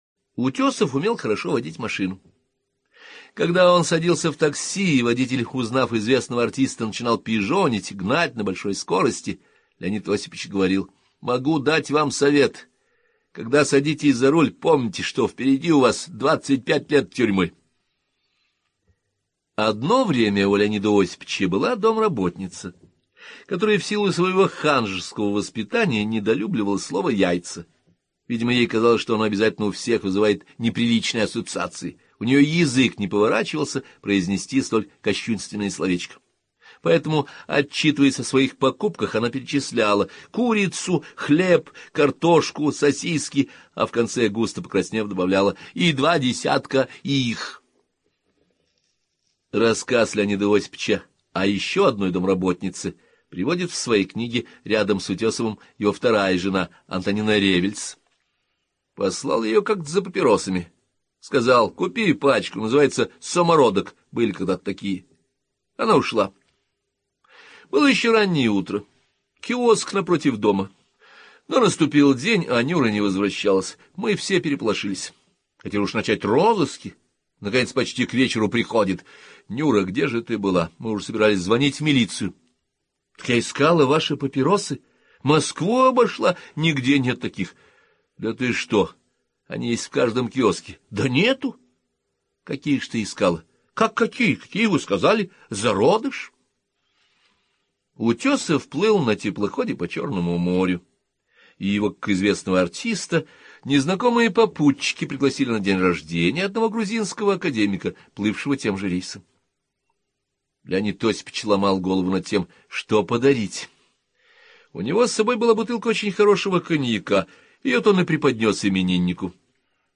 hort-aleksandr.-korol-i-svita.-zabavnyie-kartinki-iz-jizni-leonida-utesova-(chast-2).-audiokniga (1).mp3